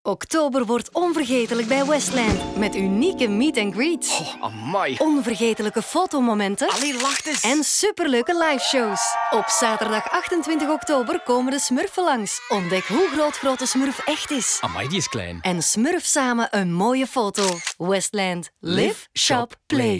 Flemish
Playful, Warm, Commercial, Friendly, Corporate
Corporate